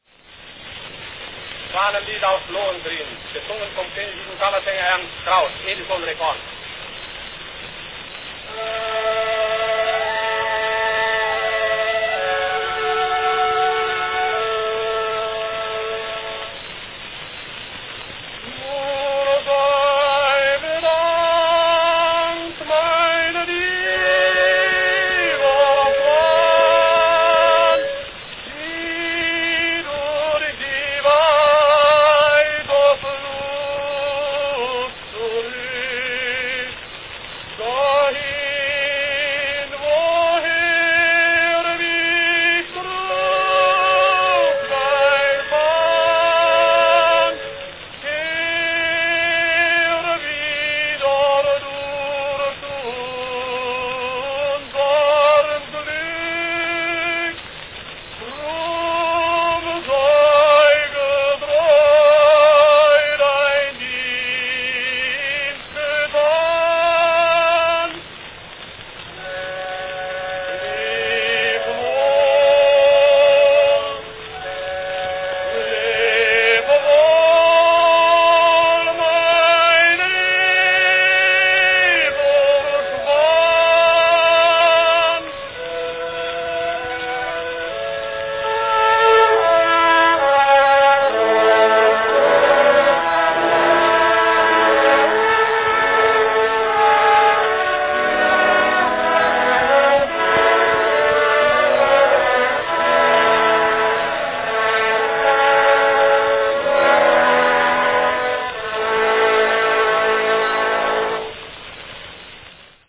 From 1904, the beautiful Schwanlied aus Lohengrin (Swan song from "Lohengrin"), sung by Ernst Kraus.
Company Edison's National Phonograph Company
Category Gesang (Song)
Performed by Ernst Kraus
(Translated from German: "Swan song from Lohengrin, sung by royal chamber singer Mr. Kraus.  Edison record.")